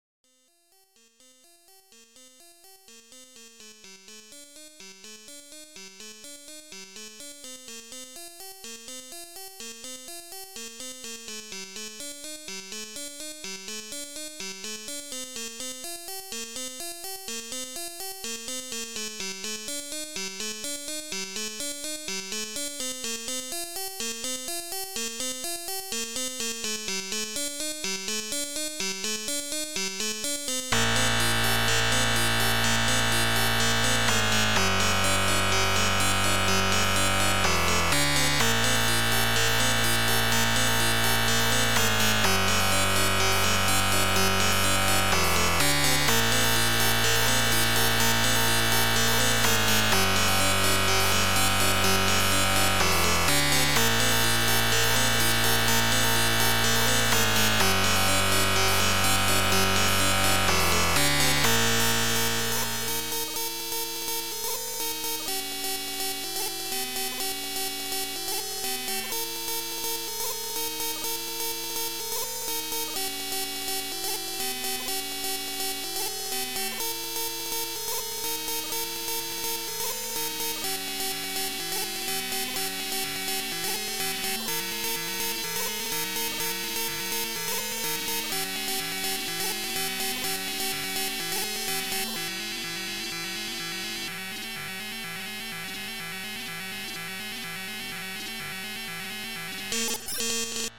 experiment in openmpt. tracker file is on my patreon, but it's probably pretty messy and poorly written lol.
trackermusic openmpt ambient experimentalmusic idm